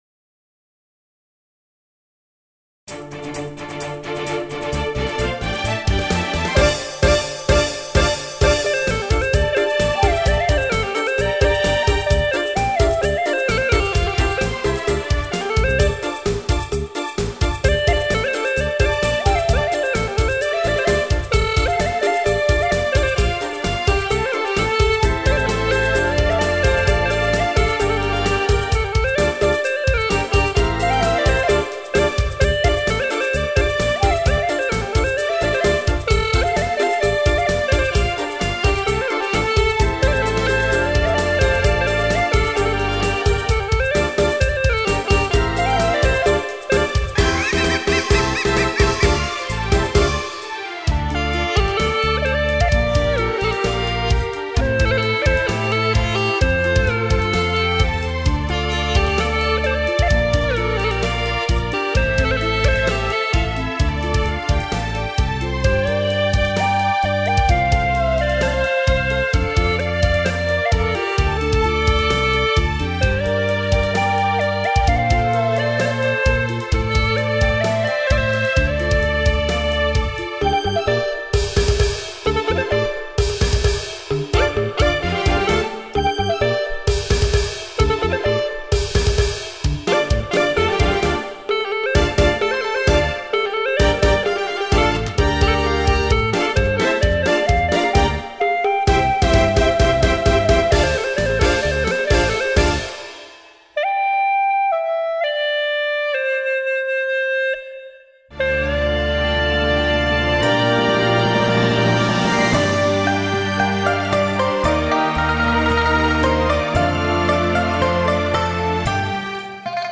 调式 : F